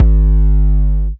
DDK1 808 8.wav